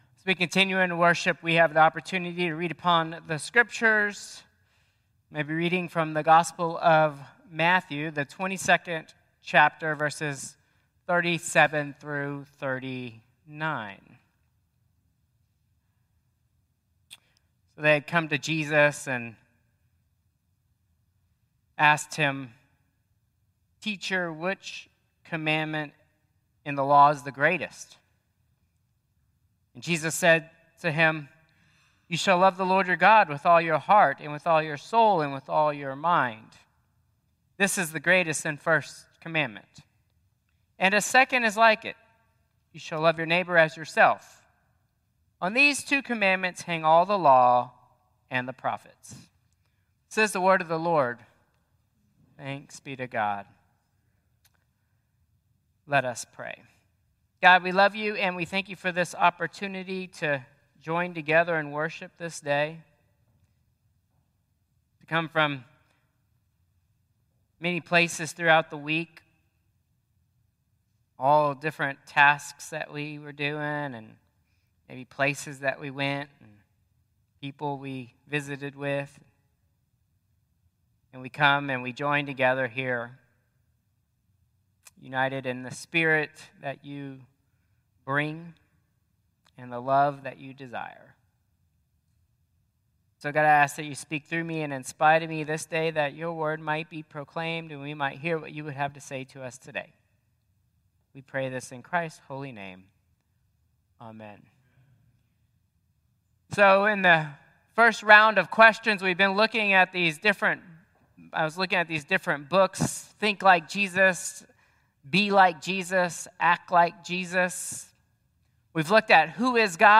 Traditional Service 5/18/2025